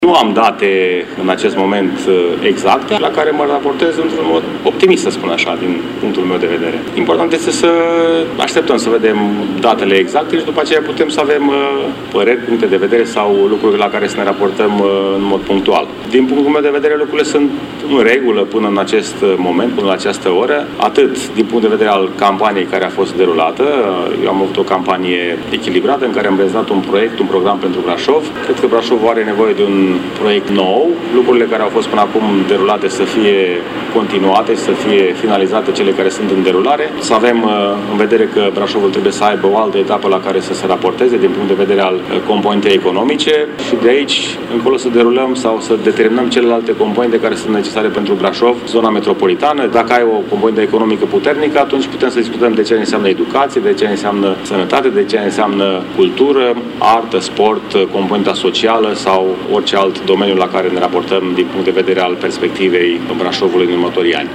La conferința de presă de astăzi, George Scripcaru a declarat: